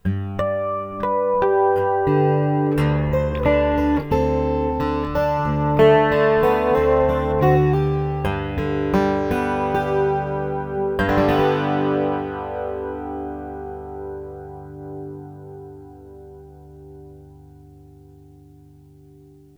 The guitar is a Martin OM-18GE with a K&K Pure Western pickup.
Clean Chorus.
Clean-Chorus.wav